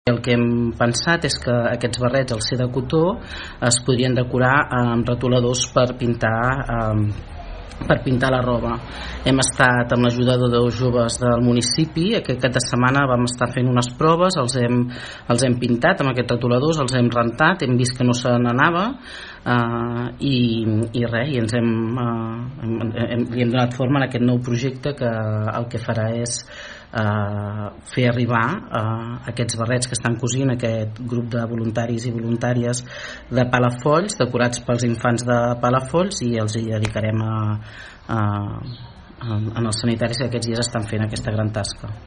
Susanna Pla, 1a tinent d’alcalde de l’Ajuntament de Palafolls, explica com s’ha ideat la iniciativa.